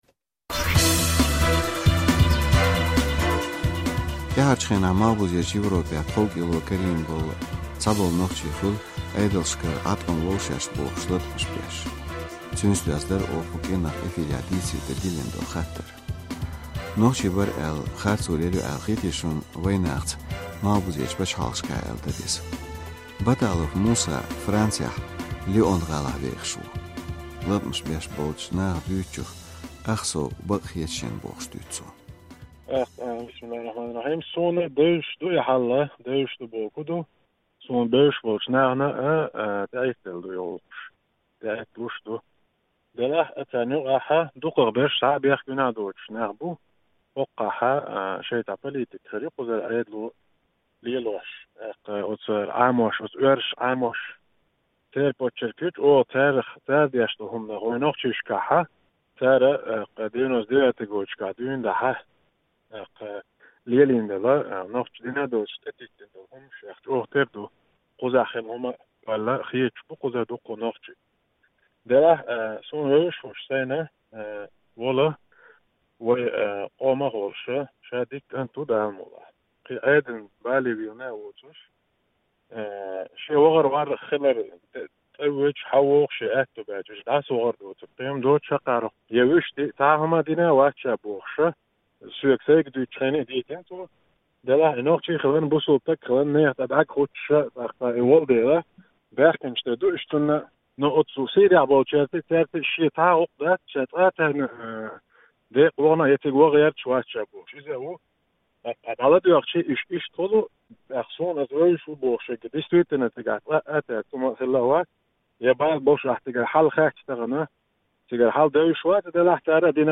ТIаьхьарчу хенахь Малхбузерчу Европехь тховкIело карийна цхьаболу нохчий хуьлу Iедалшкара Iаткъам лов шаьш бохуш латкъамаш беш. Цуьнца доьзна дарf оха кху кIиранах эфирехь дийцаре дан диллина хаттар.